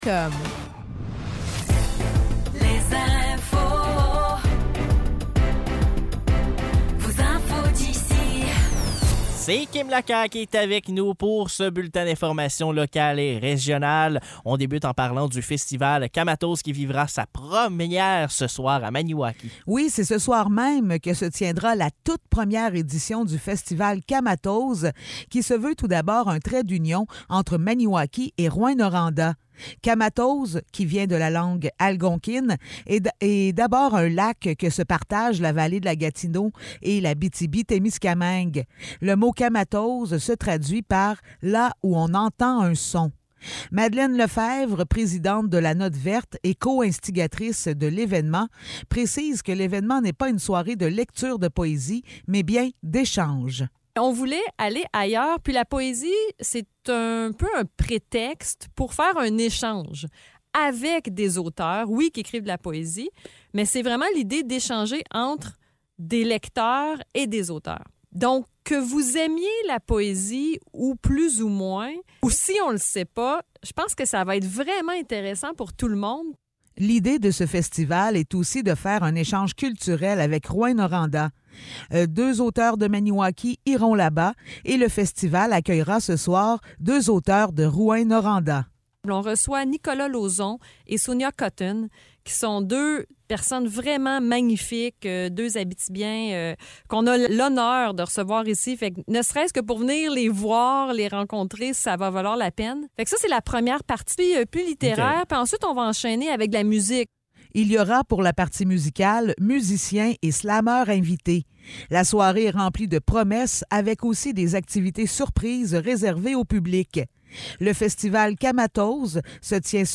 Nouvelles locales - 3 novembre 2023 - 8 h